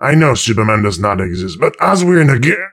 woodboxdestroyed05.ogg